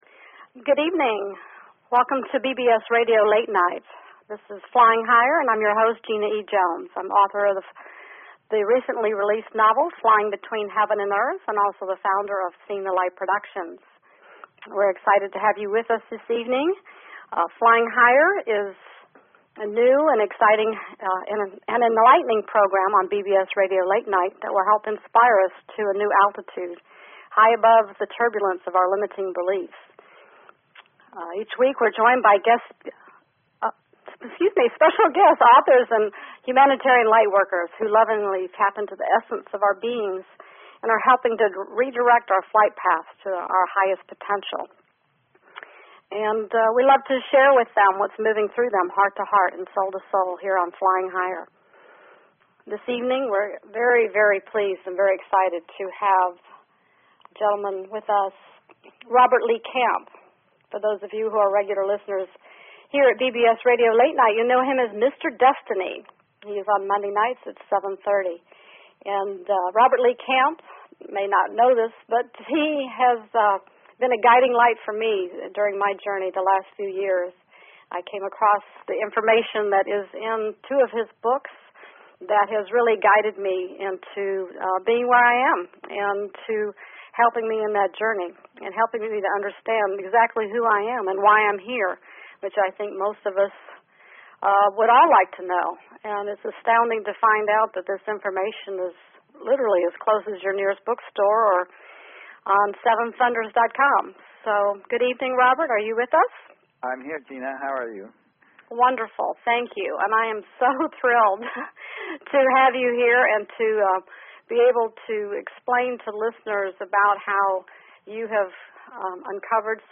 Talk Show Episode, Audio Podcast, Flying_Higher and Courtesy of BBS Radio on , show guests , about , categorized as